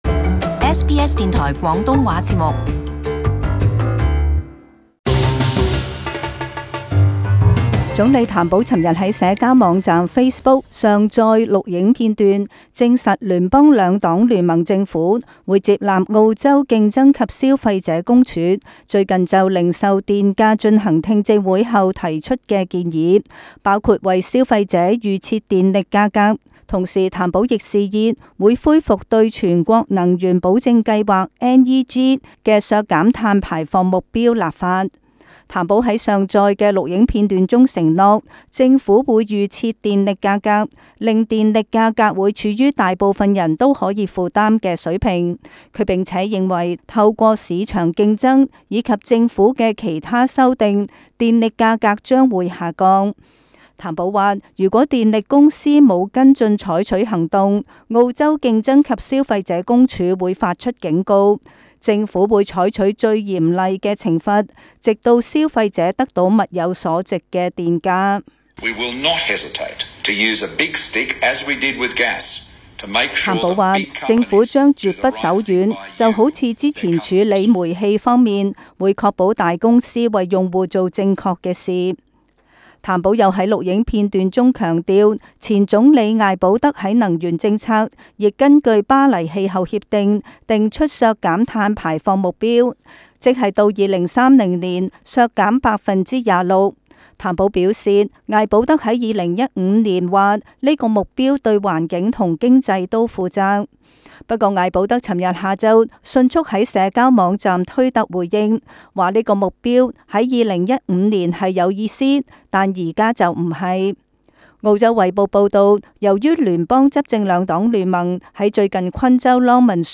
【時事報導】譚保揚言嚴懲能源公司